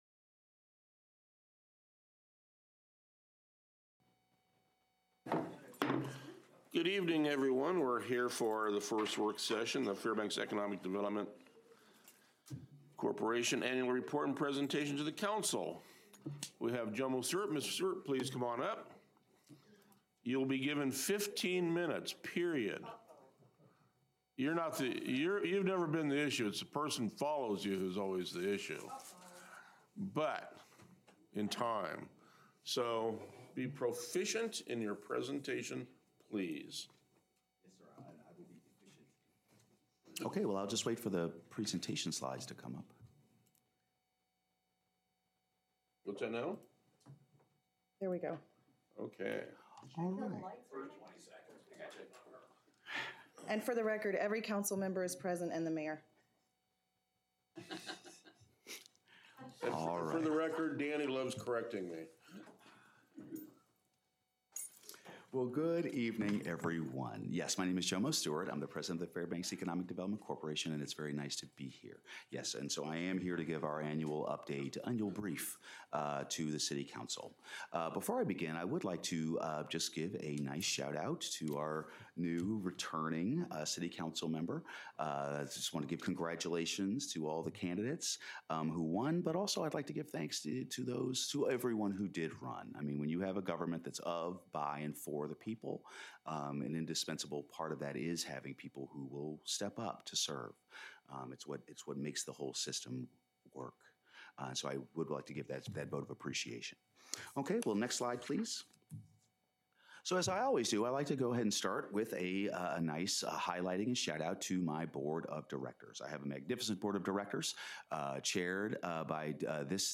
A five-minute Q&A period will follow the presentation.